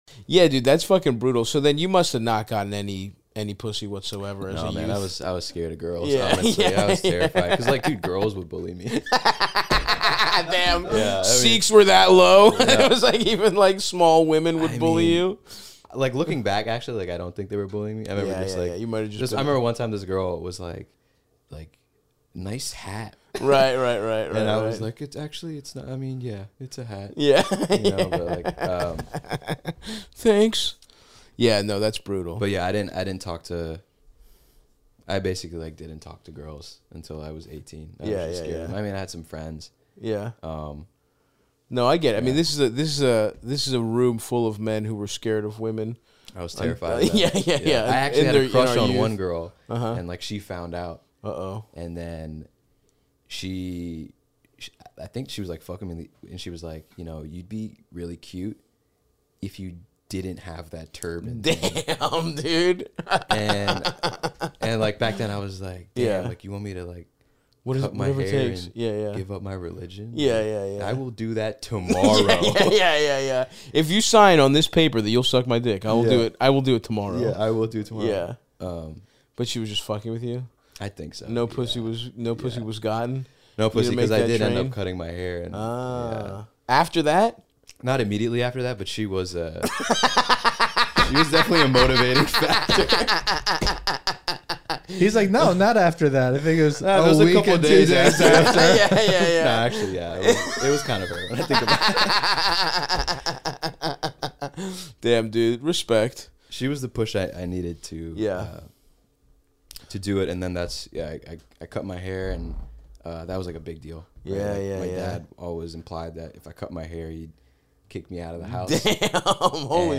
Patreon preview.
and help callers including a woman who gets insanely jealous any time her boyfriend talks to another girl and a dad who has twin sons with a woman he can’t stand.